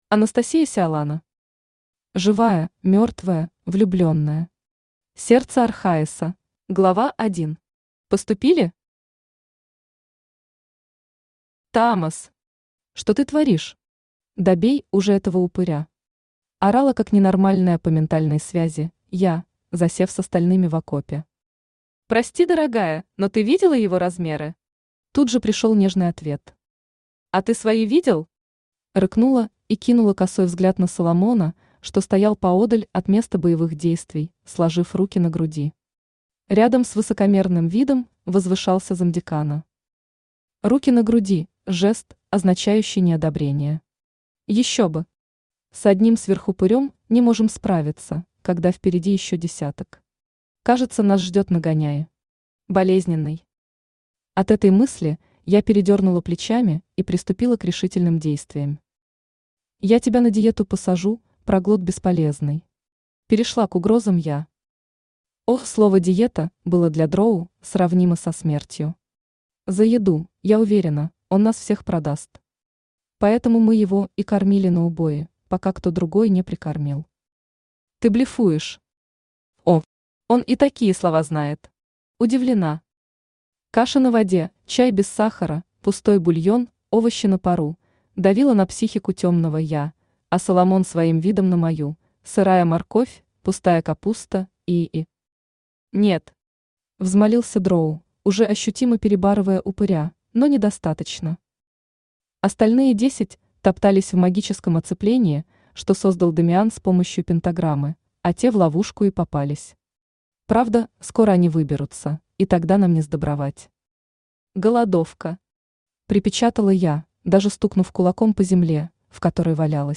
Аудиокнига Живая, мертвая, влюбленная. Сердце Архаиса | Библиотека аудиокниг
Сердце Архаиса Автор Анастасия Сиалана Читает аудиокнигу Авточтец ЛитРес.